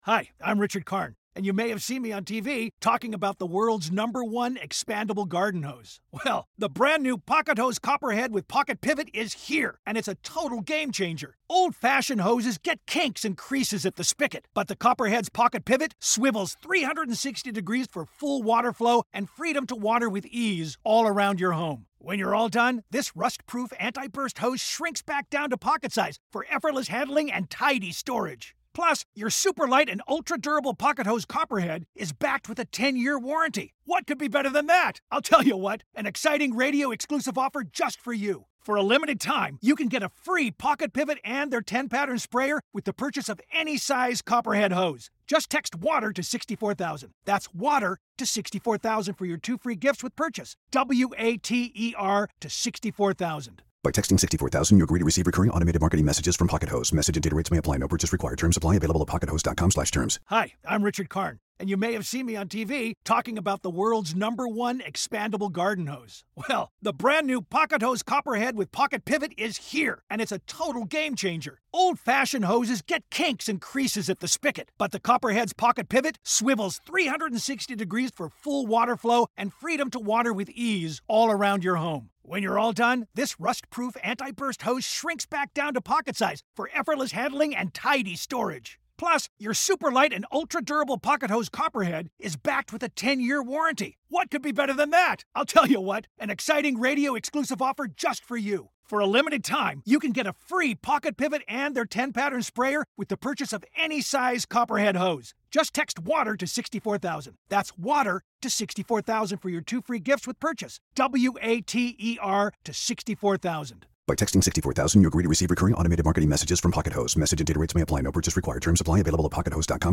Interview Special: What's it like being a video game actor?